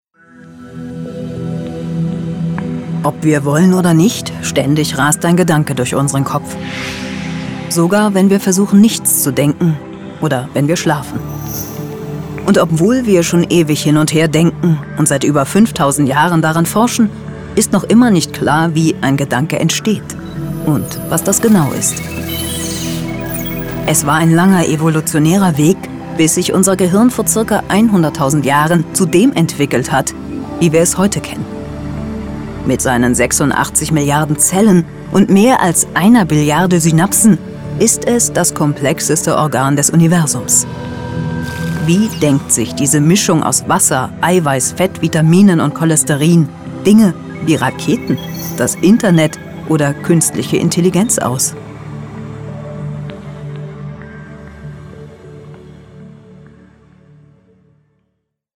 markant, sehr variabel, dunkel, sonor, souverän
Mittel plus (35-65)
Norddeutsch
Doku - Feature "Wissen"
Comment (Kommentar), Doku